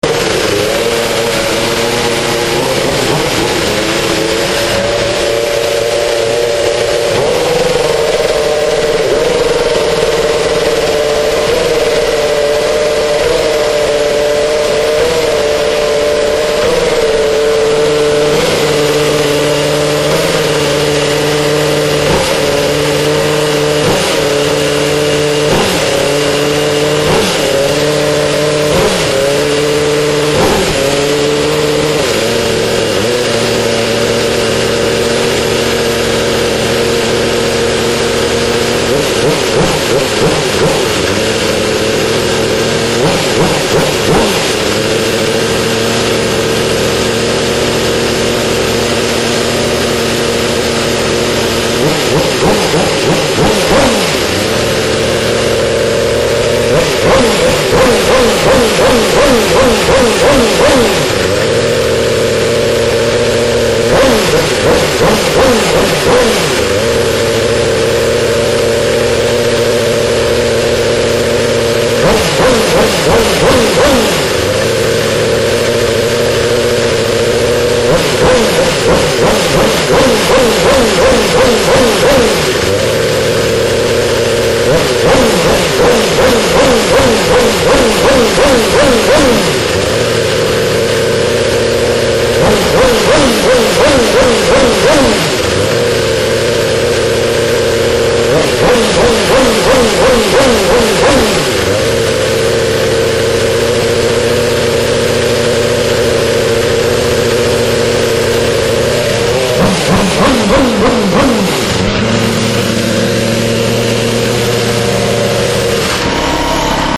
Caterham's Renault Engine Sound out of the CT01 F1 car
The 2012 sound of Caterham CT01 V8 Renault engine fire up sound.
ct01fireup.mp3